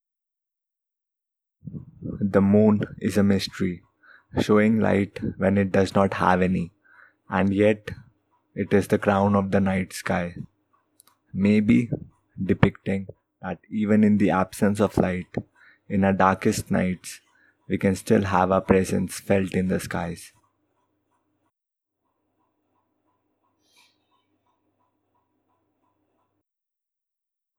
The background pump-noise occupies the same frequency-range as speech,
This is a compromise between noise reduction and voice damage.
I took the noise sample from the end of the recording.
Noise Reduction settings: 24 / 9.00 / 4